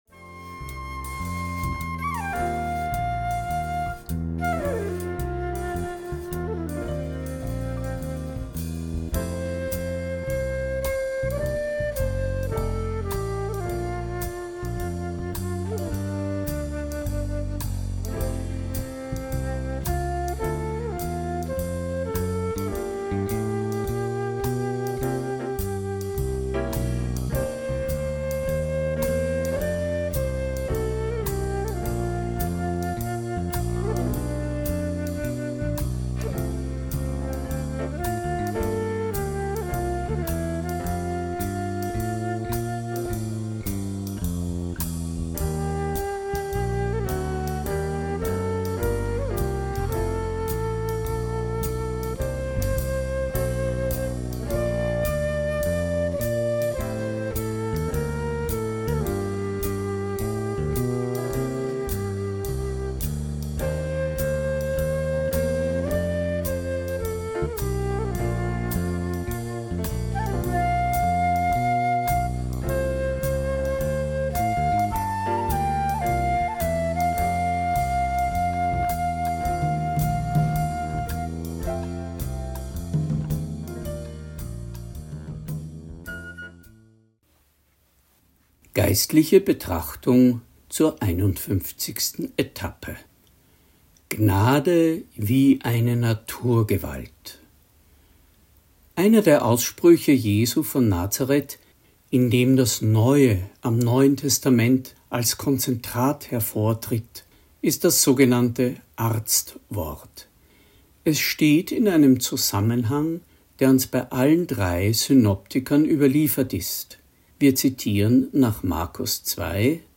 Predigt | NT02 Markus 2,17-19 Gnade wie eine Naturgewalt (Xpe51) – Glauben und Leben